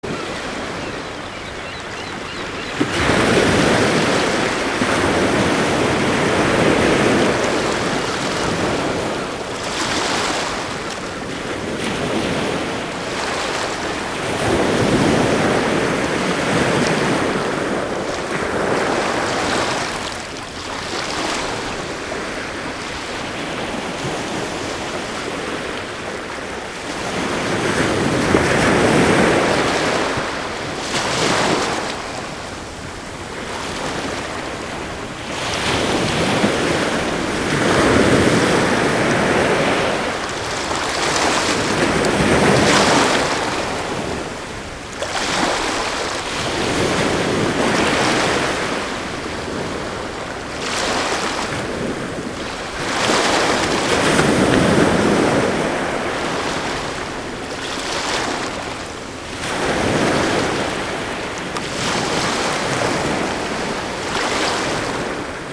Здесь вы найдете успокаивающие шум волн, грохот прибоя и шелест прибрежного песка.
Шум прибоя и крики чаек